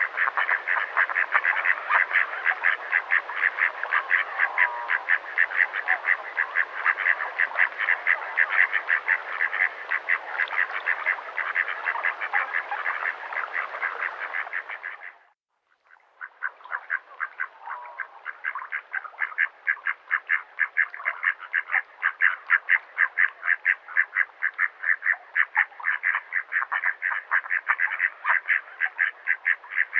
Canard colvert - Mes zoazos
canard-colvert.mp3